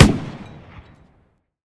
tank-mg-shot-2.ogg